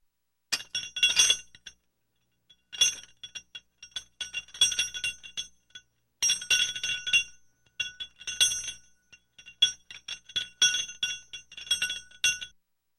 Звуки гантелей
Шум упражнений с гантелями